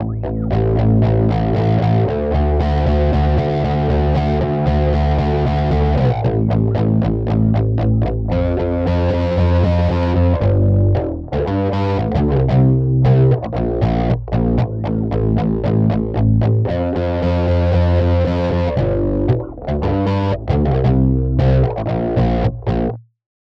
INSTRUMENT-SPECIFIC PROCESSING AND PRESETS
Song Three Bass on Bass